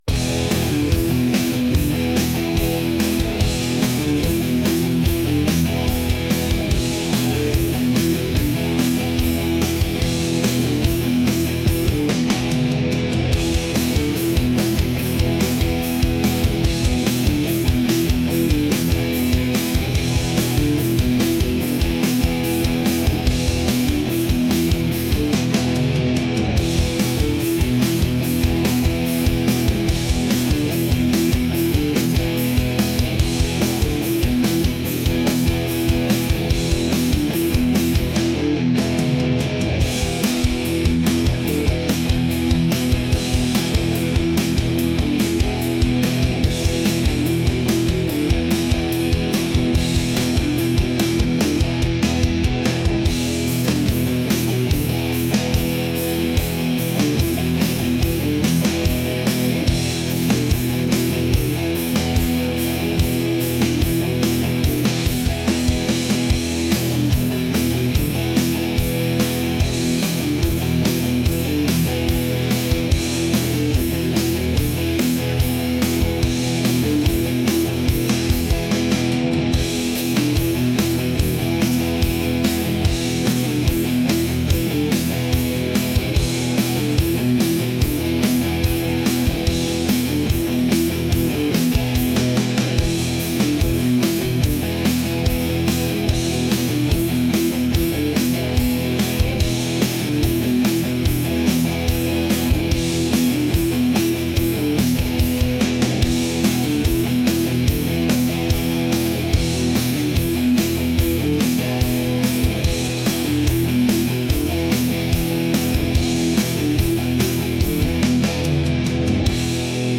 heavy | intense | rock